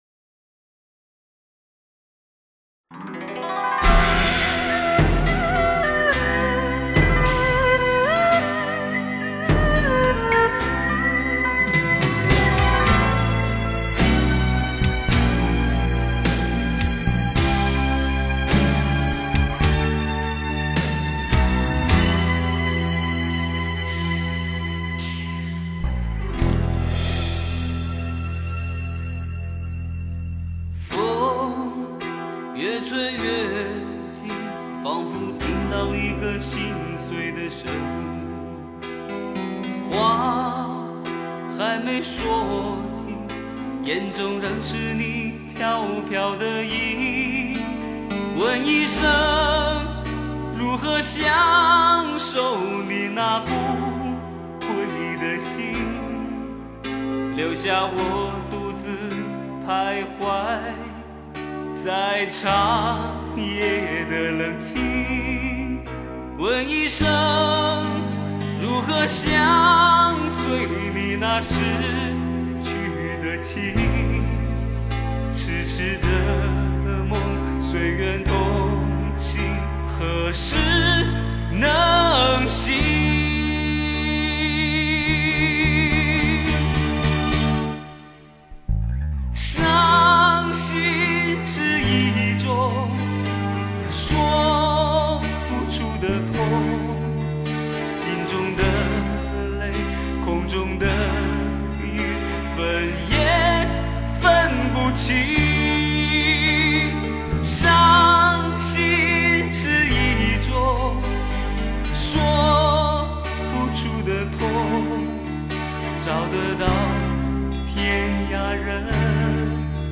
国内最著名最具有影响力的最优秀民歌手